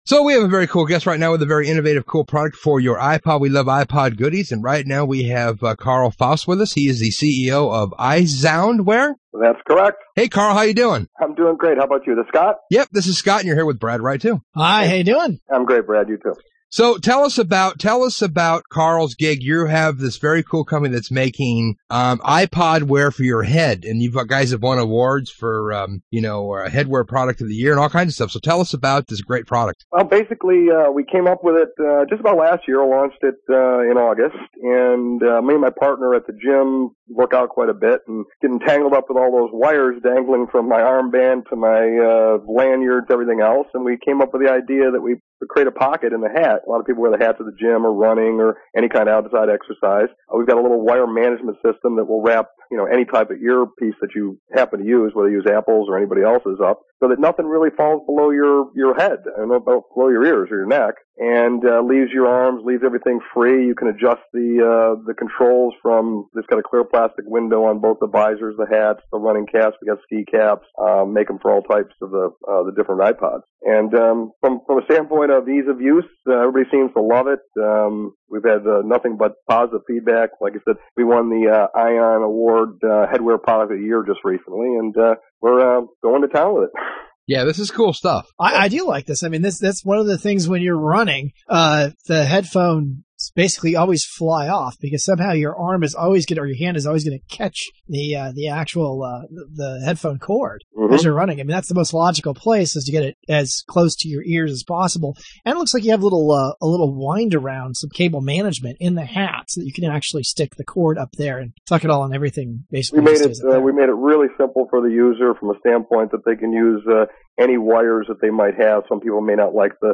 InsideMacRadio-Interview.mp3